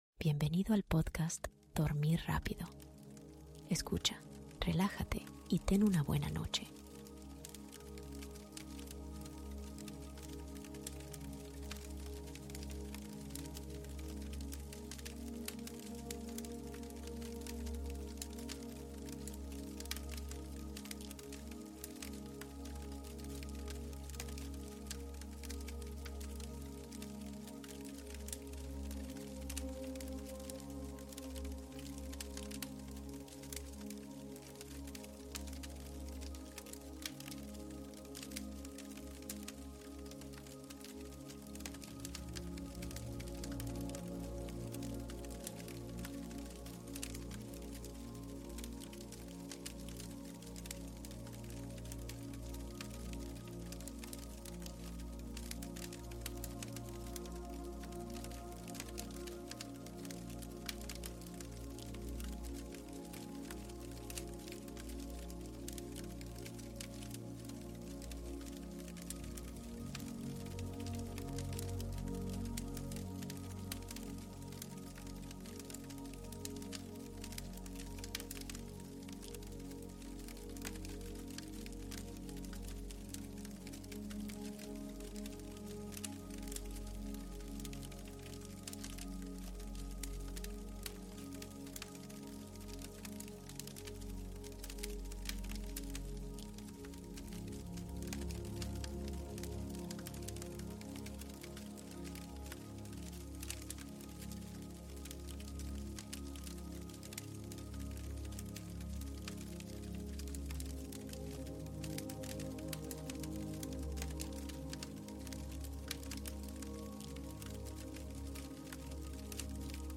DUERME al Ritmo del FUEGO y la MÚSICA Suave, para un DESCANSO Profundo